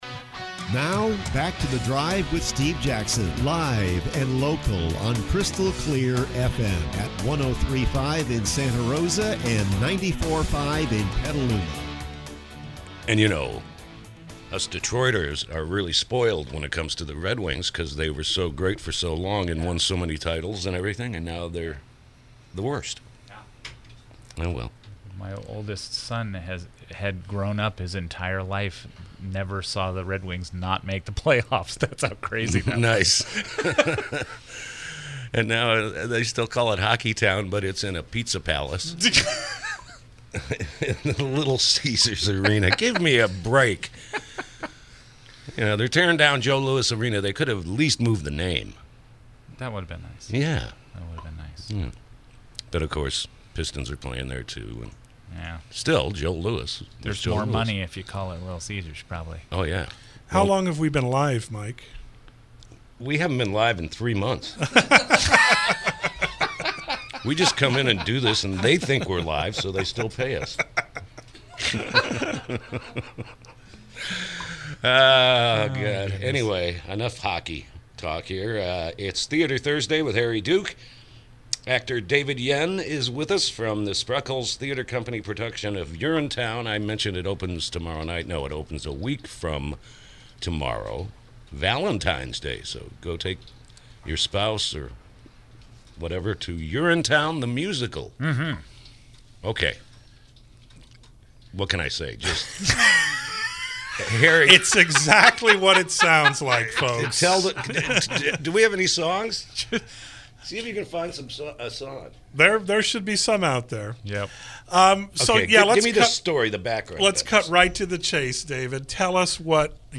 KSRO Interview – “Urinetown, the Musical”